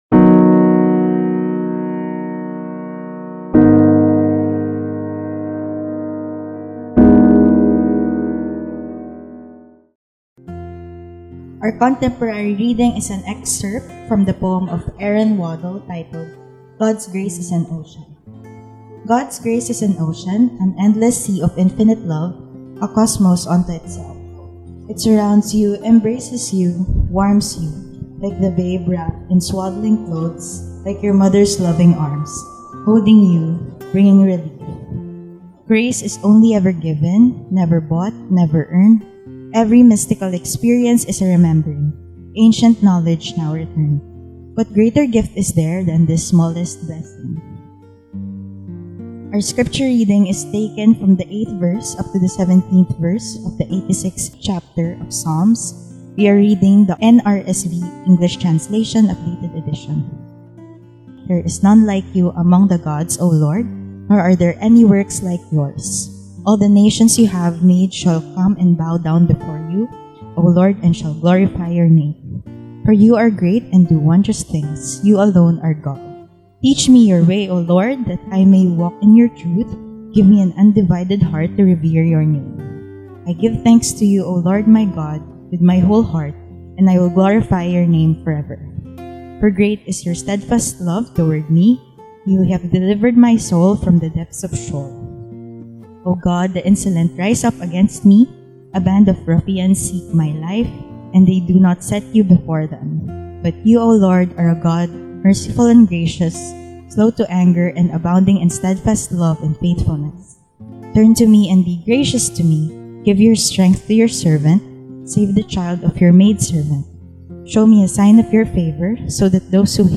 The Inclusive Church with special ministry to the LGBTQI community … continue reading 22集单集 # Religion # Christianity # San Jose # Philippines # Open Table MCC Sunday Worship